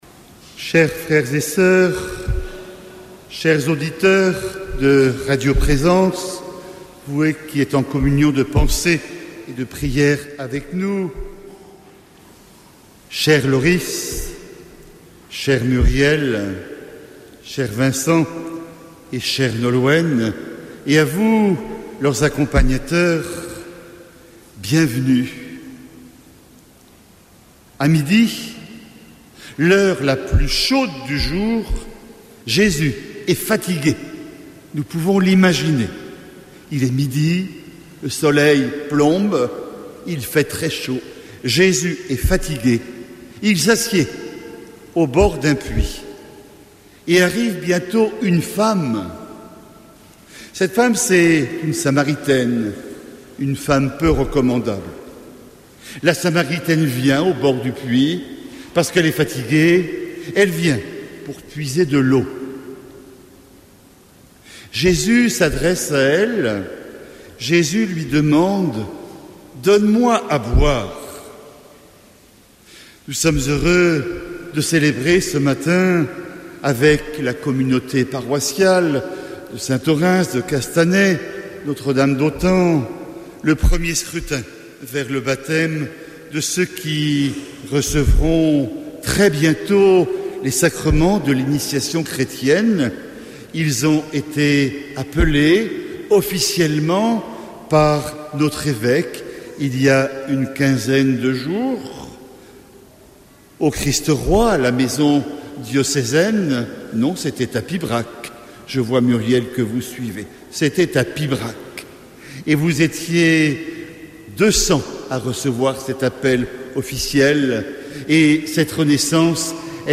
Homélie de la messe en direct de L’ensemble paroissial de Castanet-Tolosan et Notre-Dame-d’Autan